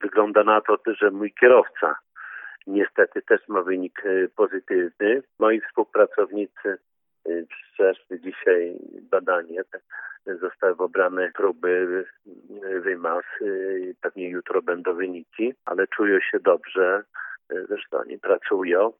Poniżej cała rozmowa z prezydentem Suwałk: